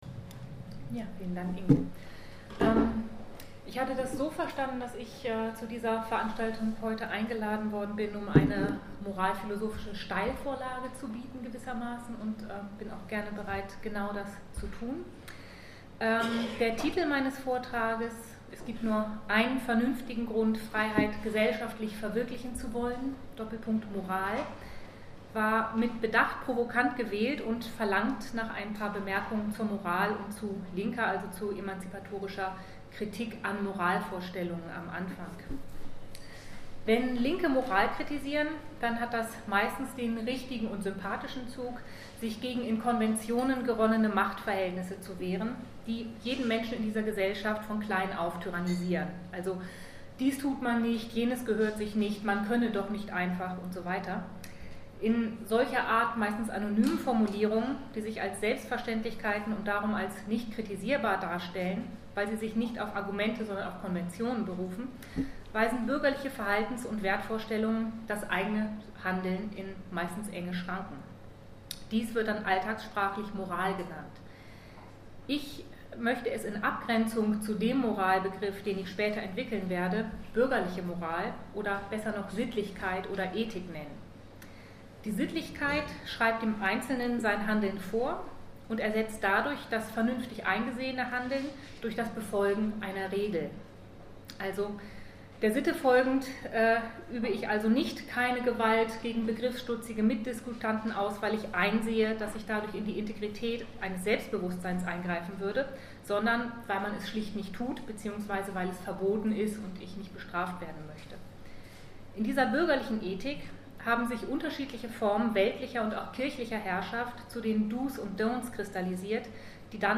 Text/Vortrag im Archiv für kritische Gesellschaftstheorie